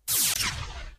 gameover.ogg